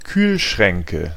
Ääntäminen
Ääntäminen Tuntematon aksentti: IPA: /ˈkyːlˌʃʀɛŋkə/ Haettu sana löytyi näillä lähdekielillä: saksa Käännöksiä ei löytynyt valitulle kohdekielelle. Kühlschränke on sanan Kühlschrank monikko.